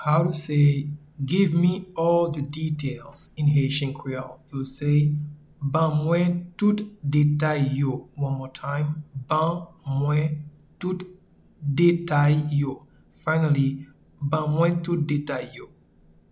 Pronunciation and Transcript:
Give-me-all-the-details-in-Haitian-Creole-Ban-mwen-tout-detay-yo.mp3